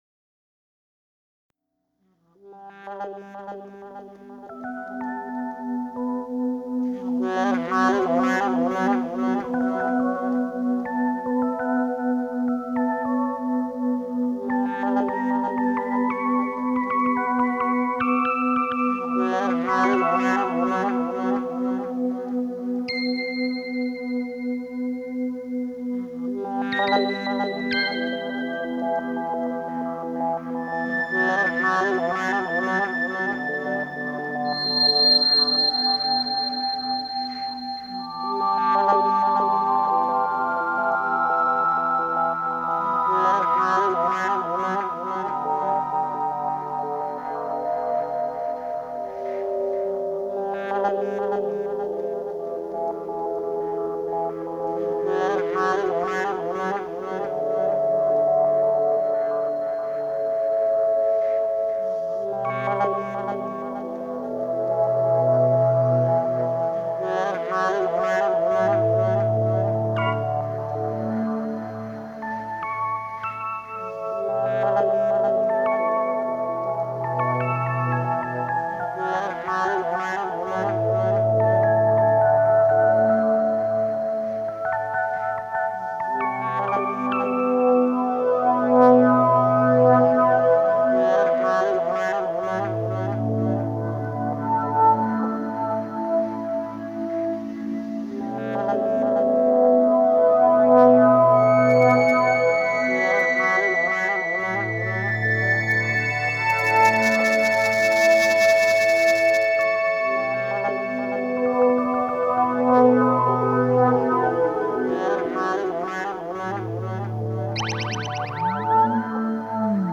analog synths
saxophone
This is Acid Jazz for the 21st century.